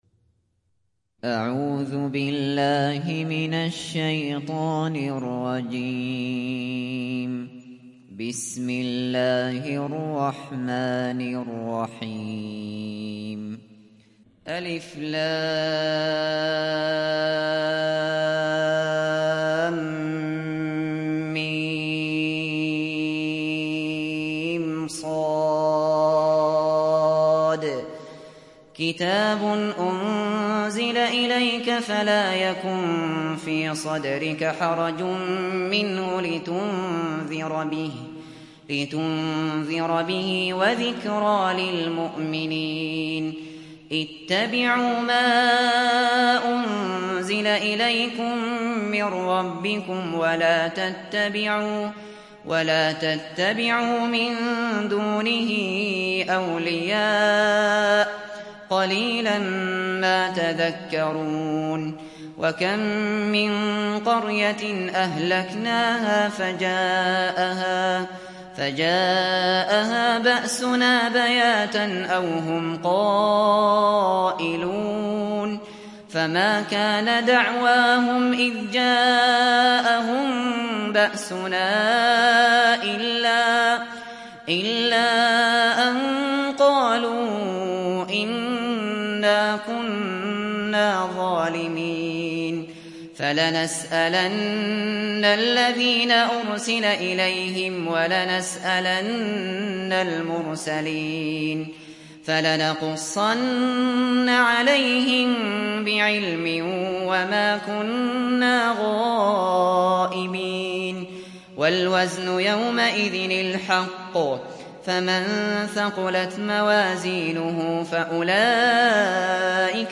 Araf Suresi İndir mp3 Abu Bakr Al Shatri Riwayat Hafs an Asim, Kurani indirin ve mp3 tam doğrudan bağlantılar dinle